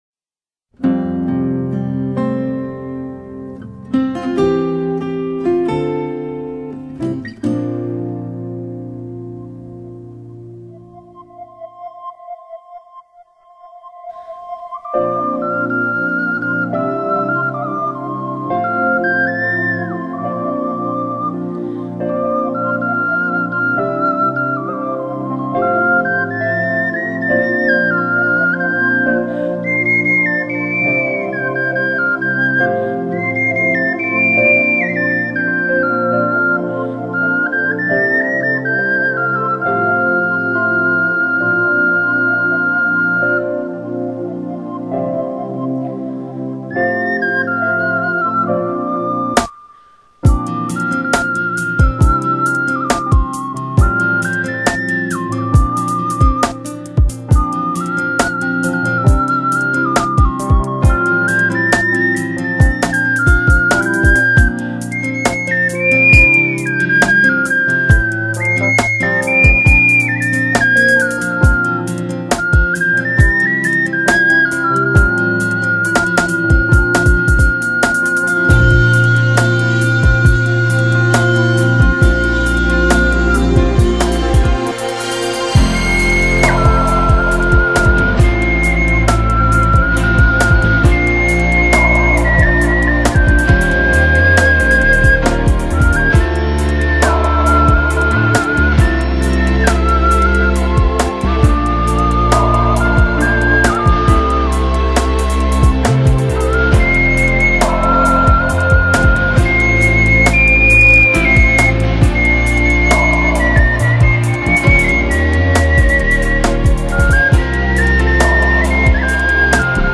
오카리나 연주곡 베스트 모음